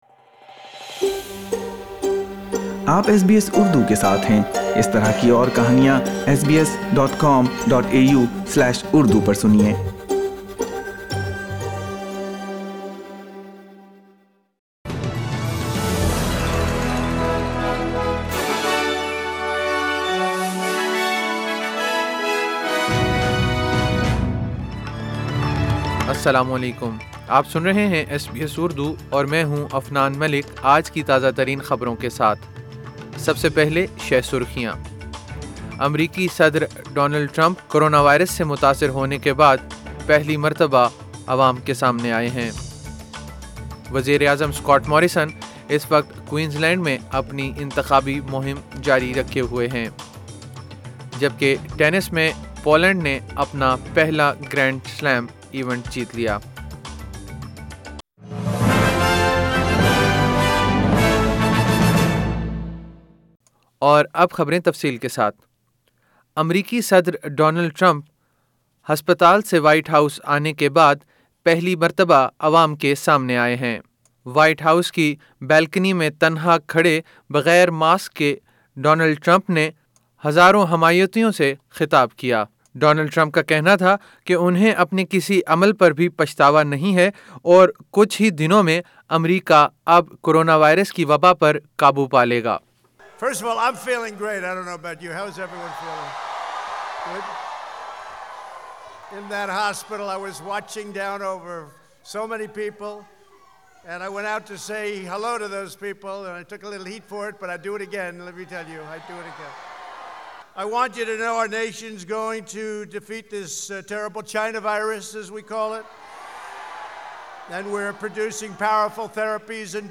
ایس بی ایس اردو خبریں 11 اکتوبر 2020